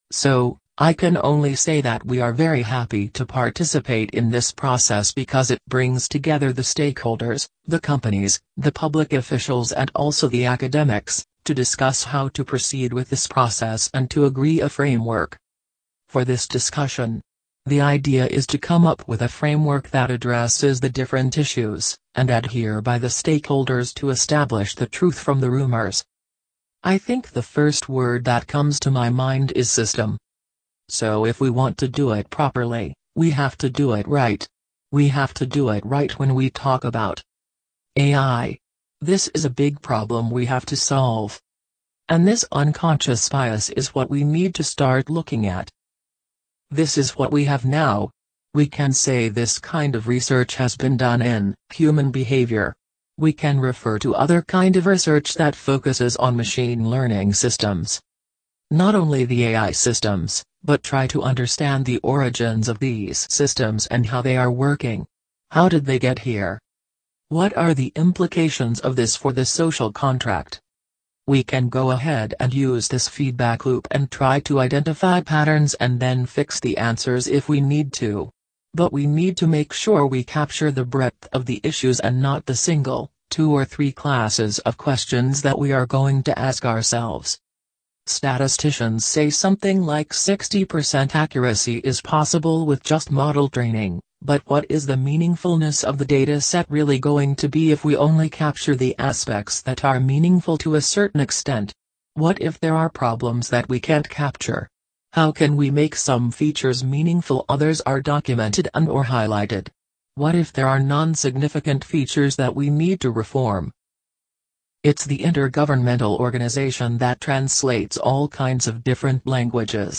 artificial inteligence performance Interactive installation and performative intervention conducted during the Internet Governance Forum 2019, using the open-source GPT-2 model to generate synthetic text based on the input of policy papers and transcripts from previous sessions at the IGF.
It was an experiment in vivo, to observe what would happen if a neural network was to generate a speech on regulating AI based on previous IGF discussions, and include that speech to play out in the setting of AI policy experts. The content it produced resembled a poetic or dadaist stream of consciousness, including some segments of completely coherent text, out of which its speech was extracted.
ai-speaker-speech.mp3